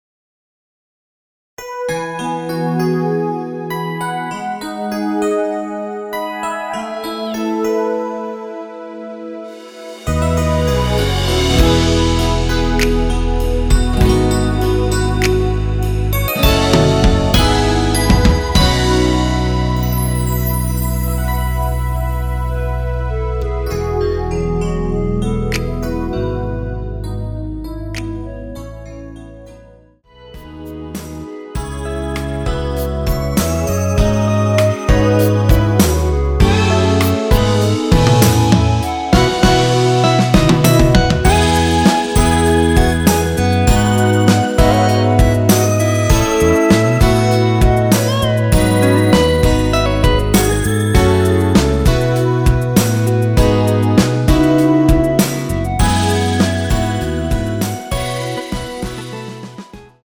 원키에서(+2)올린 멜로디 포함된 MR입니다.
멜로디 MR이라고 합니다.
앞부분30초, 뒷부분30초씩 편집해서 올려 드리고 있습니다.
중간에 음이 끈어지고 다시 나오는 이유는